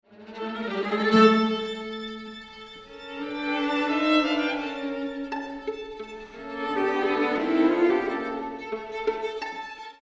Quatuor String Quartet